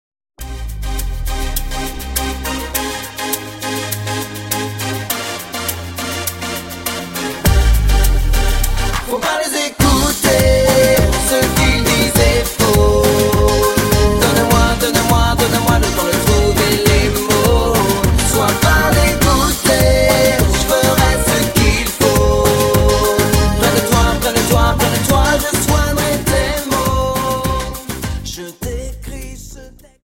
Dance: Samba 51